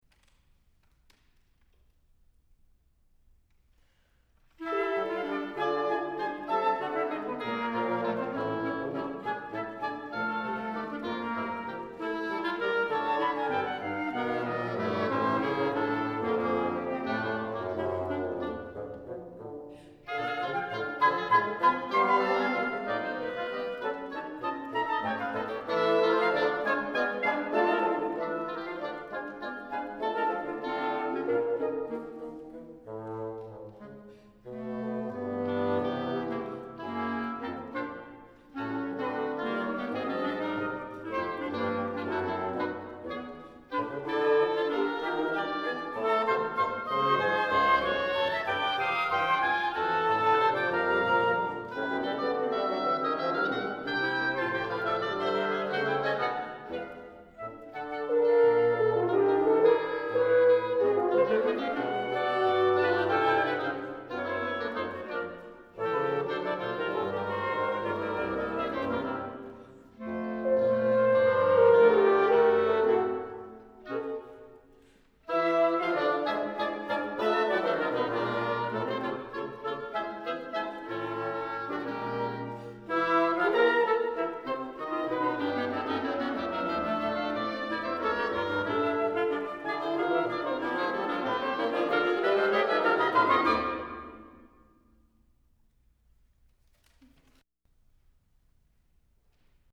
Wind Quintet (1996)
It is similar in many ways to my saxophone quartets: short and dense.
This live recording is the work of five freshmen at Amherst and various other Five Colleges.